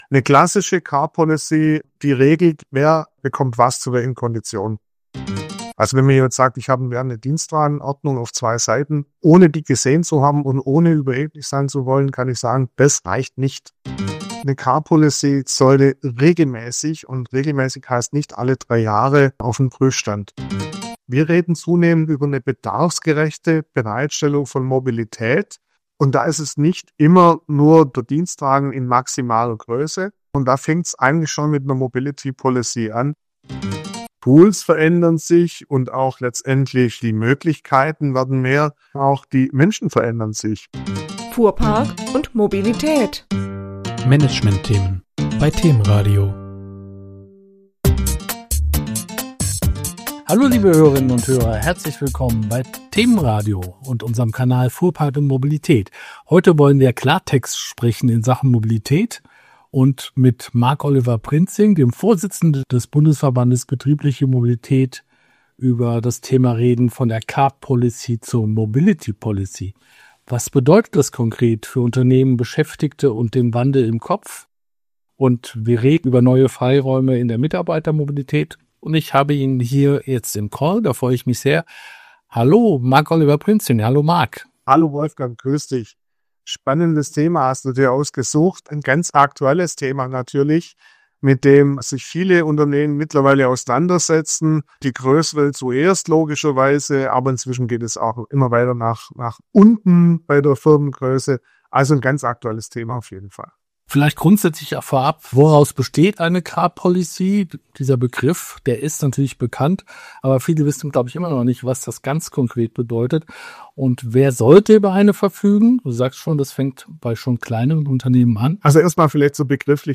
Ein Gespräch über Verantwortung, Chancen – und den Mut, Mobilität neu zu denken.